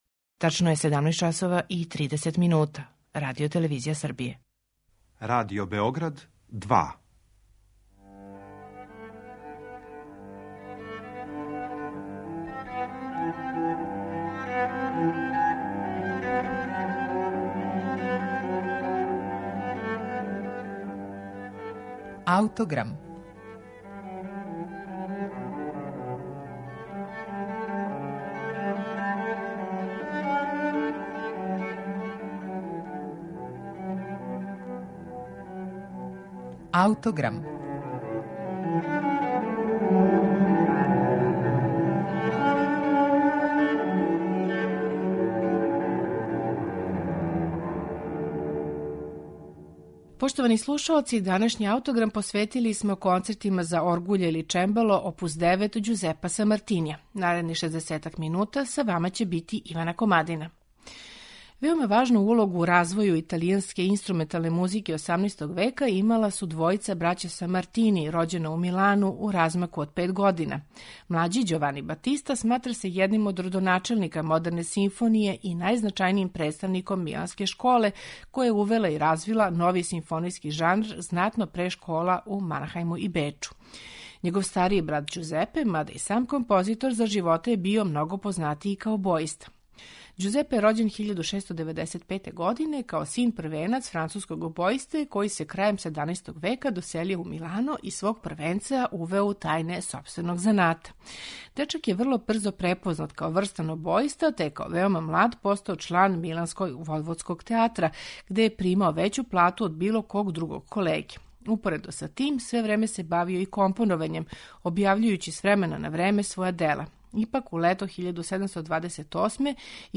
Ђузепе Самартини: Концерти за оргуље
оргуљаша